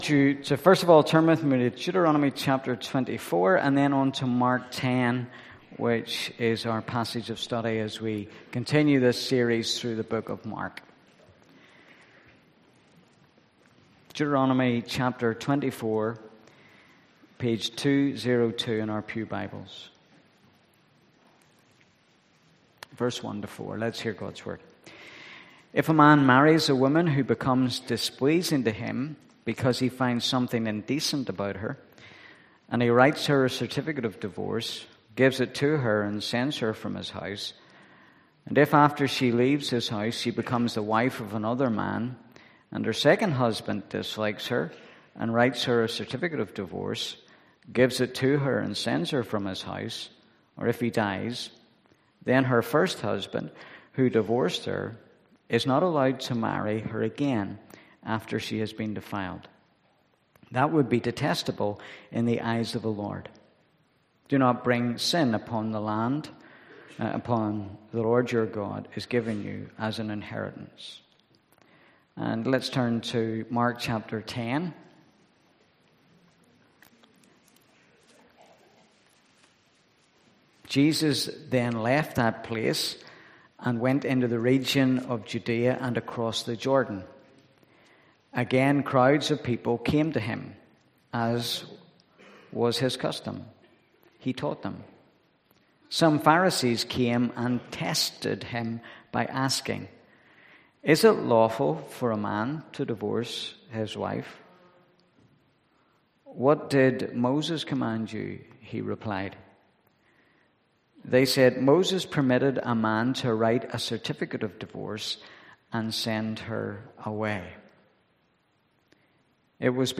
& Deuteronomy 24 v 1-4 Service Type: am Bible Text: Mark 10 v 1-12.